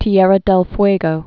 (tē-ĕrə dĕl fwāgō, tyĕrrä dĕl fwĕgō)